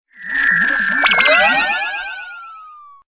SA_head_shrink_only.ogg